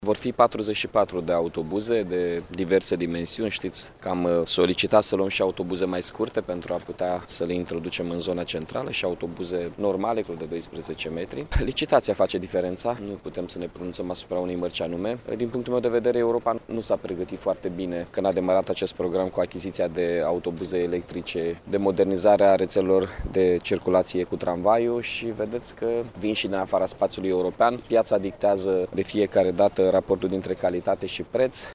Mihai Chirica, aflat astăzi la o prezentare de autobuze electrice realizate de un producător german, şi-a exprimat regretul că în Uniunea Europeană nu există o politică de susţinere a firmelor producătoare în domeniu, în timp ce în Turcia şi China, funcţionează astfel de strategii economice.